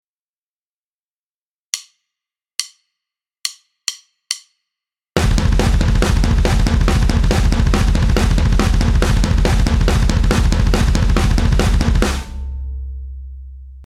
ツーバスを使った擬似連打フレーズ
「フロア・バス・バス」を中心にしたフィルインで、『速い』と『フロアの音がバスに似ている』ことから、あたかも6連符を踏んでいるように聞かせることができます。
右手をフロアで8分刻み。その間に両足で右左(左右)と入れていきます。
ウラ打ちをオモテ打ちに変えたパターンです。
※デモ音源はXLN AudioのAddictive Drums(アクティブドラムス)という打ち込みのソフトで作っています。
テンポは140で統一しています。